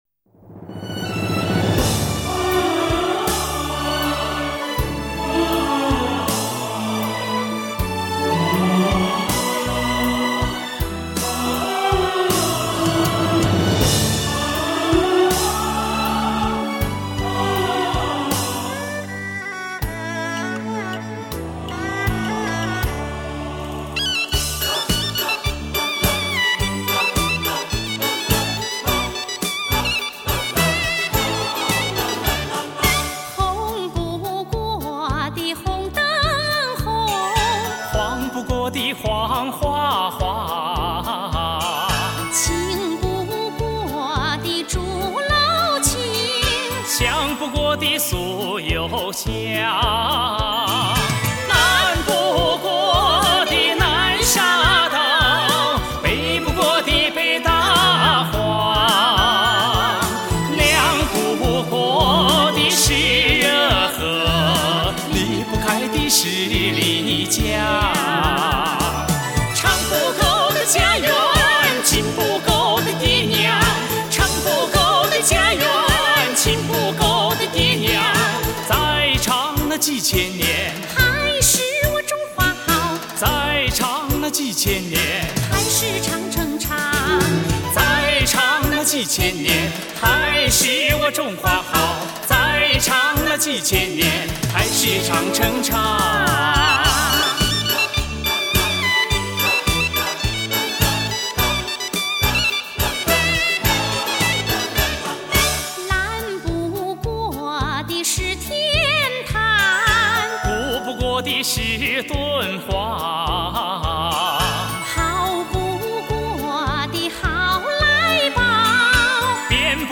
中国传统吉祥贺岁歌，给你整年好喜气。
16首热闹滚滚的年节庆典新春英文歌，为你点缀愉悦的氛围，欢庆缤纷璀璨好时光！